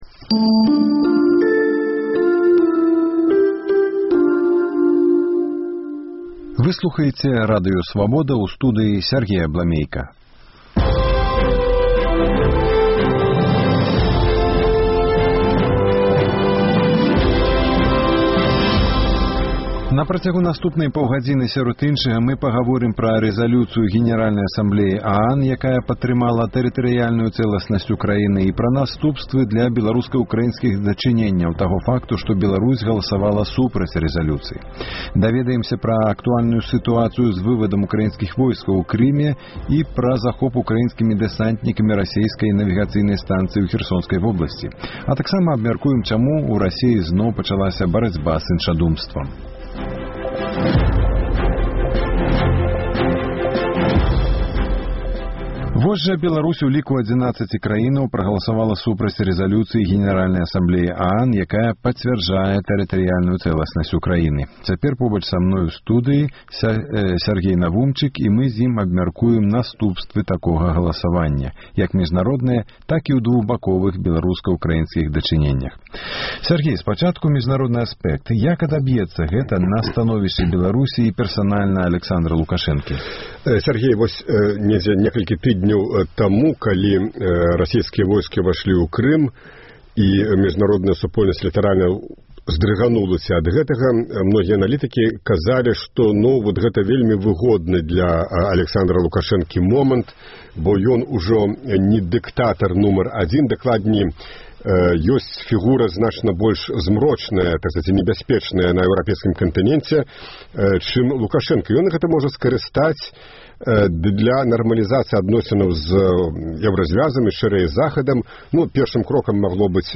Украінскія дэсантнікі ўзялі пад кантроль навігацыйную станцыю «Марс-75» у Генічаску Херсонскай вобласьці, якая з часоў распаду СССР належыць Чарнаморскаму флёту Расеі. Рэпартаж з Украіны.